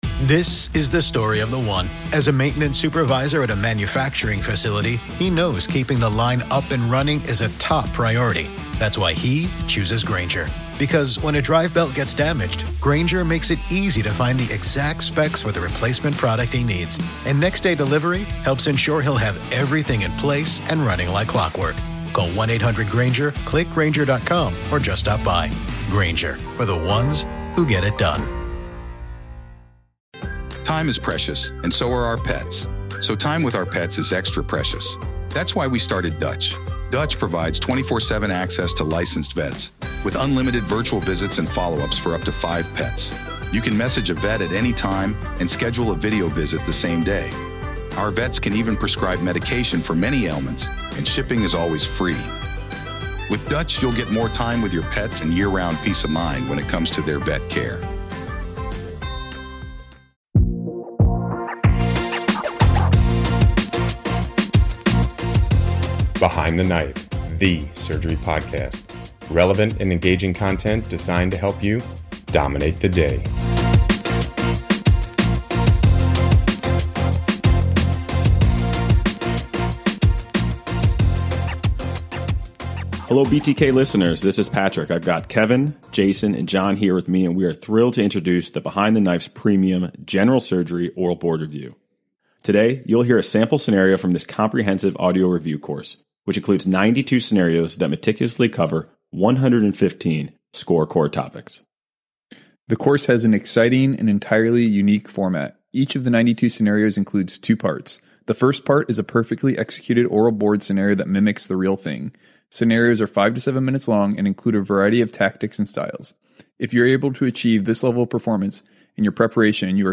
The first part is a perfectly executed oral board scenario that mimics the real thing.
The second part introduces high-yield commentary to each scenario. This commentary includes tips and tricks to help you dominate the most challenging scenarios in addition to practical, easy-to-understand teaching that covers the most confusing topics we face as general surgeons.